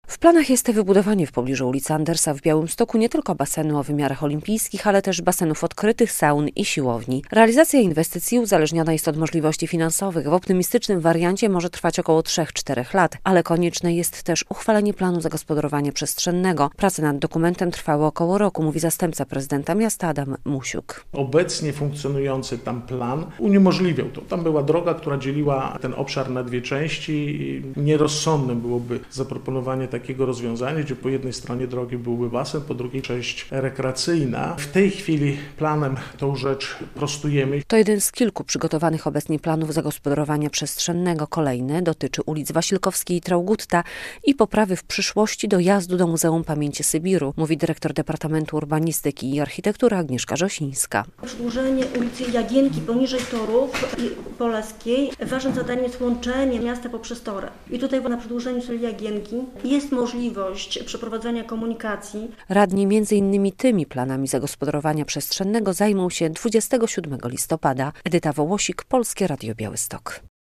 Jest plan zagospodarowania przestrzennego dla terenu, gdzie ma powstać basen o wymiarach olimpijskich - relacja